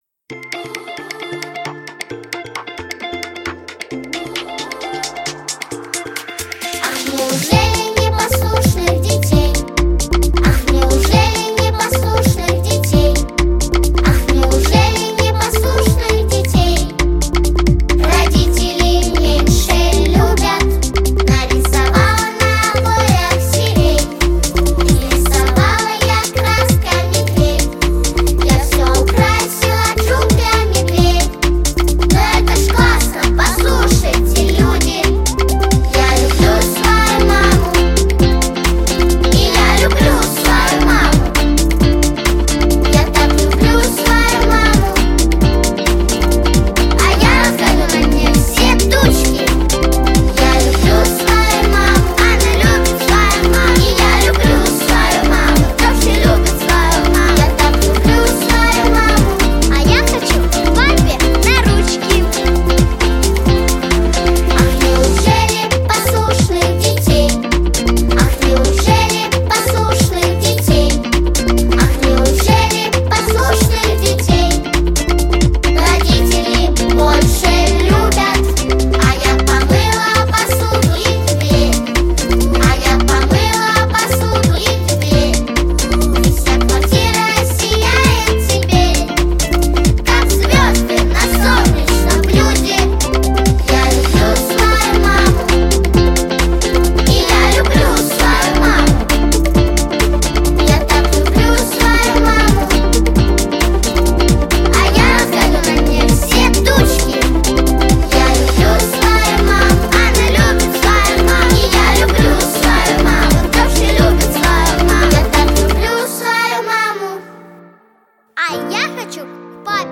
🎶 Детские песни / О близких людях / Песни про маму